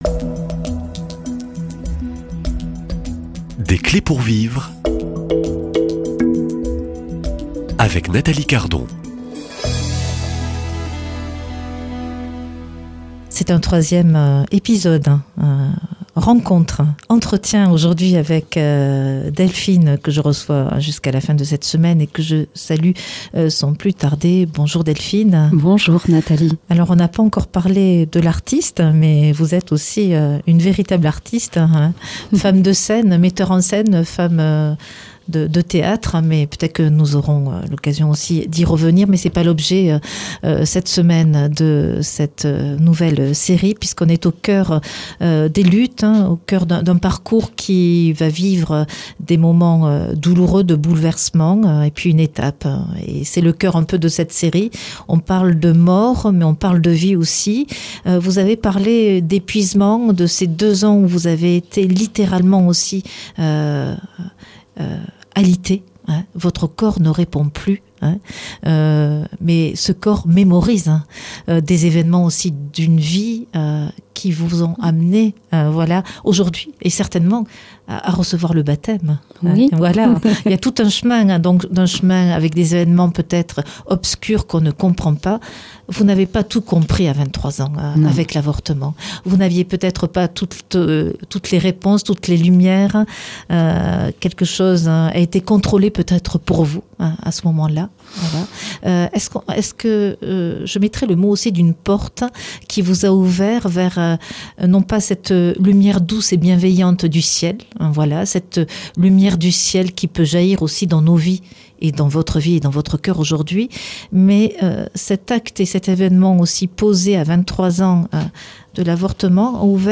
Un témoignage poignant de transformation, de guérison et de résilience.